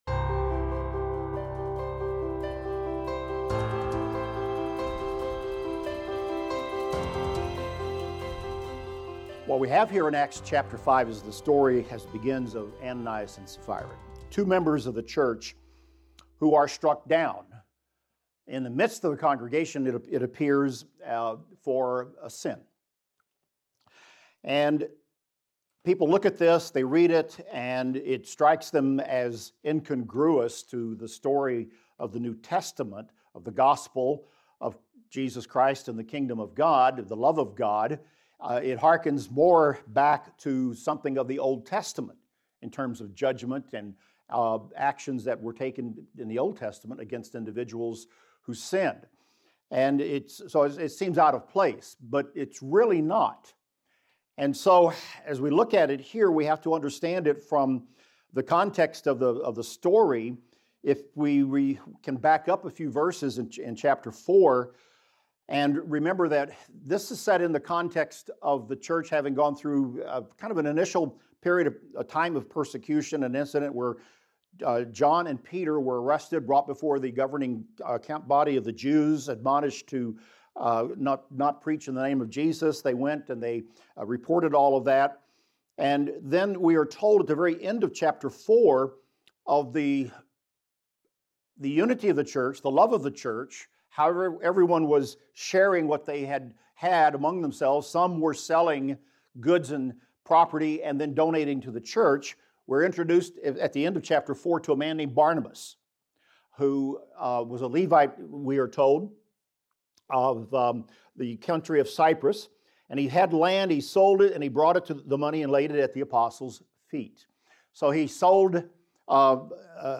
In this class we will discuss Acts 5:1-25 and look at the following: the story of Ananias and Sapphira and the importance of integrity; the excitement surrounding numerous healings; and the apostles being imprisoned, miraculously released and then teaching at the temple.